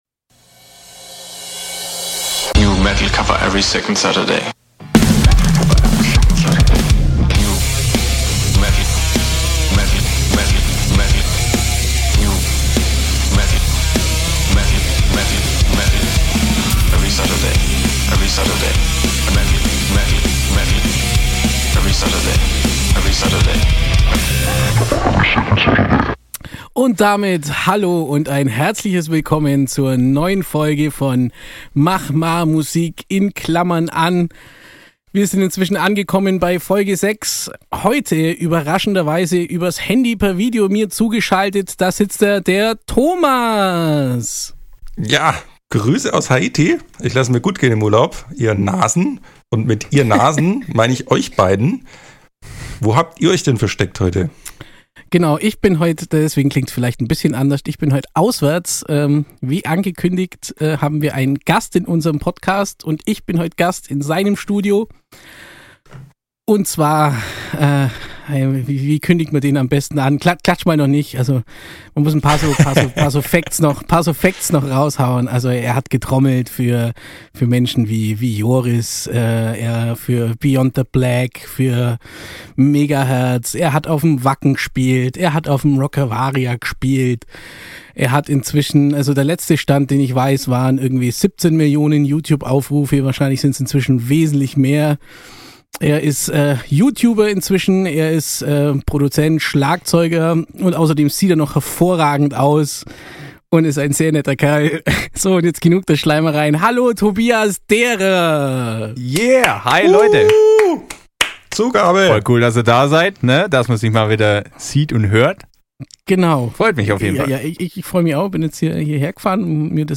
Wir haben wieder einen Gast! Und zwar einen waschechten Youtuber!